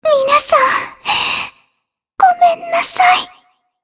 Shea-Vox_Dead_jp.wav